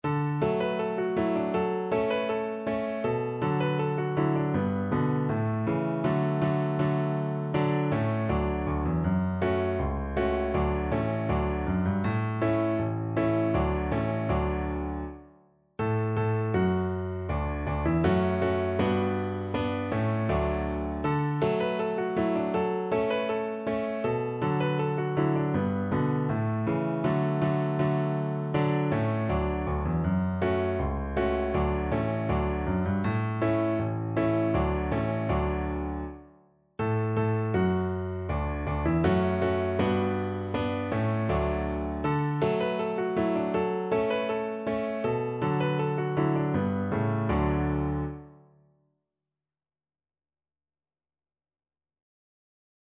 Traditional Trad. Ba luobo (Pull the Carrots) Viola version
World Asia China Ba luobo (Pull the Carrots)
Viola
D major (Sounding Pitch) (View more D major Music for Viola )
2/4 (View more 2/4 Music)
Steadily =c.80
Traditional (View more Traditional Viola Music)